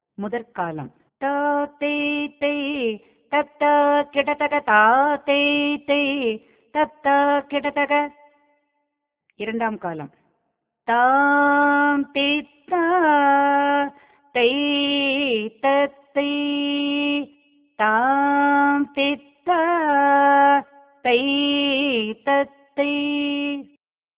நிருத்த வகையான ஆடல் இது.
அடவுச் சொற்கட்டுகளை நட்டுவனார் தத்தகாரத்தில் சொல்வார்.
பக்க இசையாளர் கம்பீர நாட்டை இராகத்தில் கோவையாக
இது திச்ர (மூன்று) ஏக தாள அலாரிப்பு